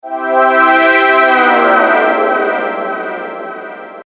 | ambient pad effect |